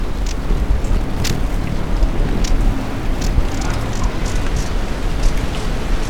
GLITTERING-BELLIED EMERALD Chlorostilbon lucidus
Chlorostilbonaureoventris.wav